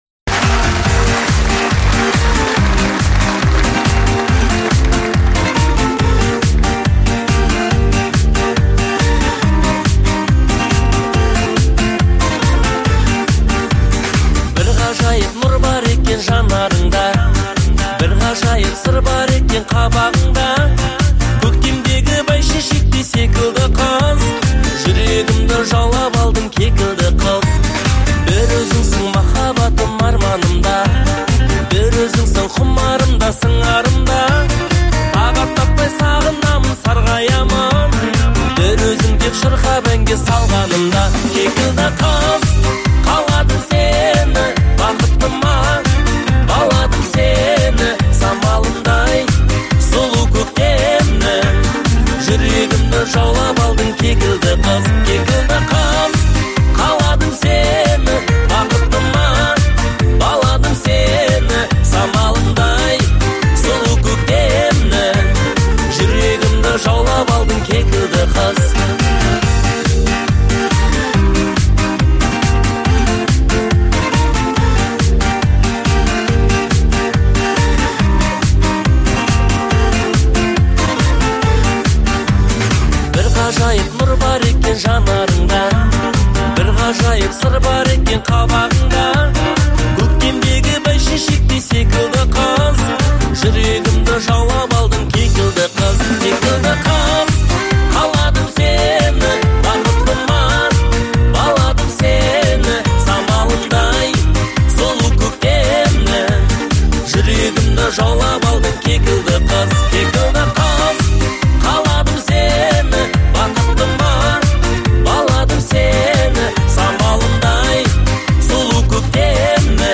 это мелодичная песня в жанре казахской поп-музыки